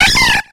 Cri de Granivol dans Pokémon X et Y.